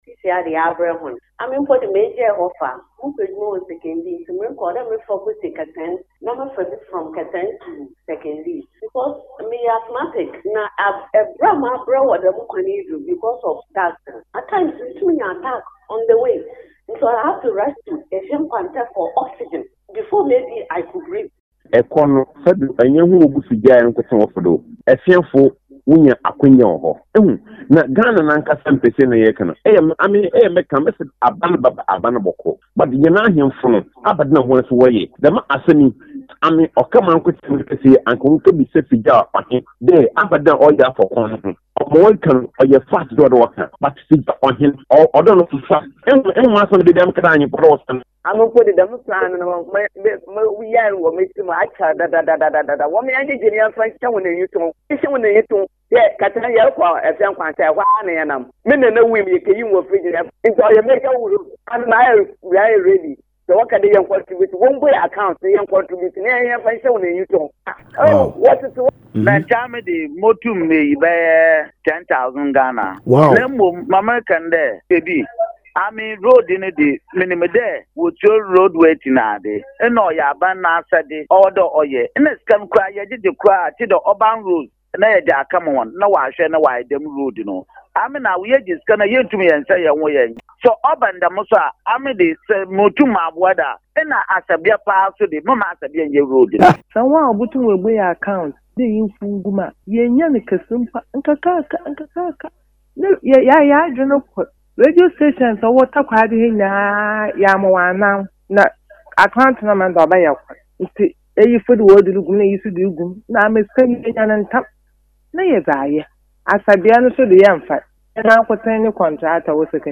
Callers, mainly from the Sekondi, Fijai, Essikado and other neighbouring communities attested to the effect the delay in completing works on the road has had on their health, transportation cost, and cost of maintenance for their vehicles.
Here is an excerpt of the conversation: